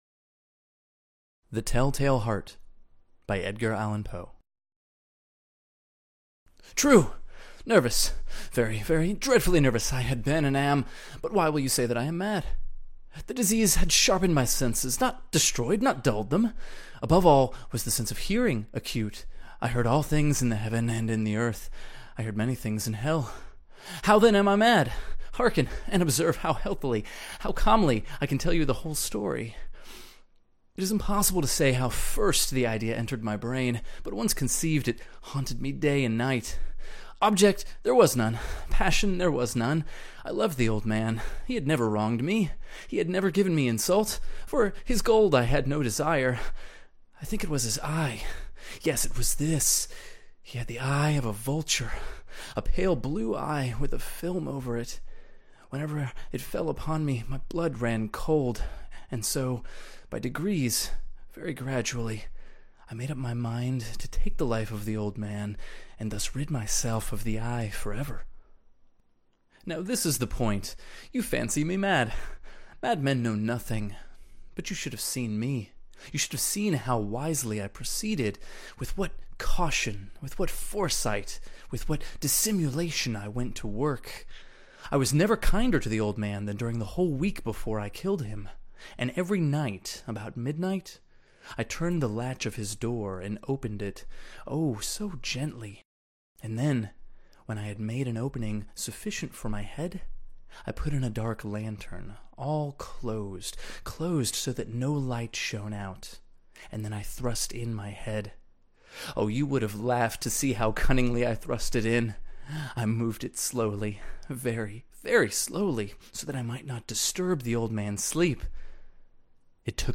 The Tell-tale Heart by Edgar Allen Poe – Audio Book (1843)
the-tell-tale-heart-by-edgar-allan-poe-audiobook.mp3